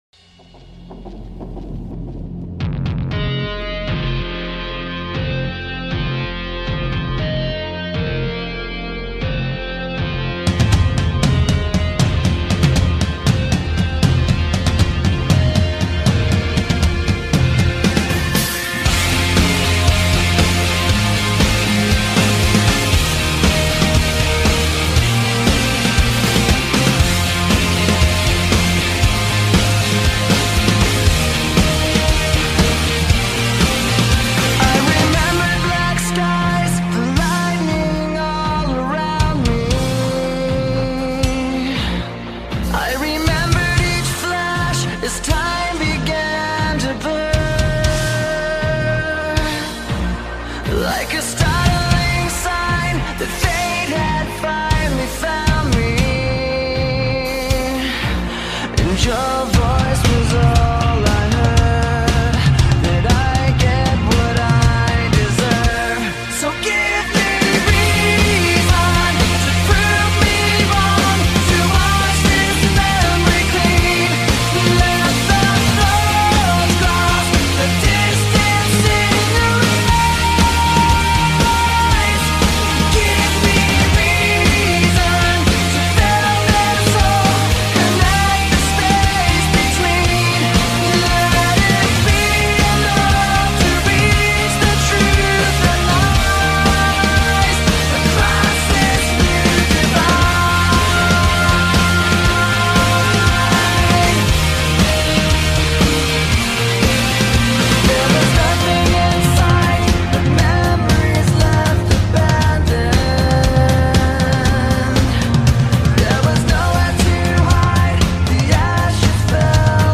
Ударные